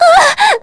Lewsia_B-Vox_Damage_kr_02.wav